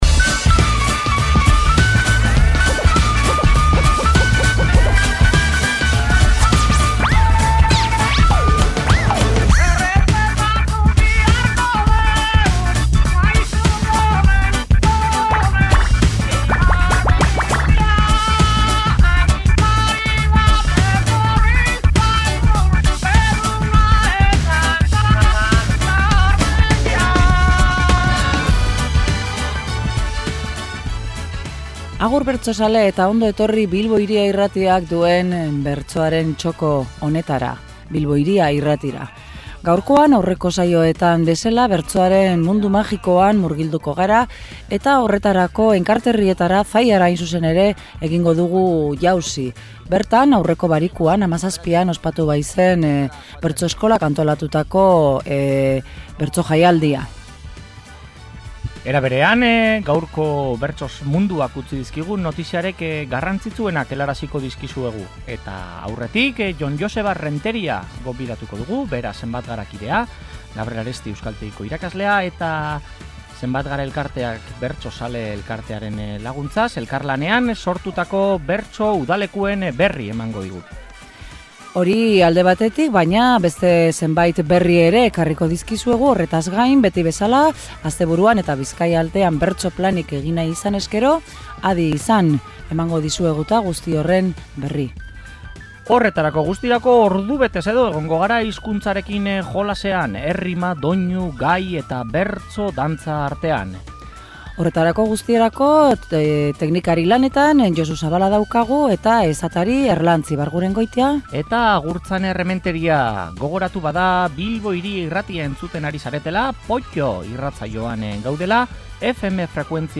POTTO: Zallako bertso-saioa
Enkarterrietako herri horretan izandako bertso-saioa entzungai izan dugu.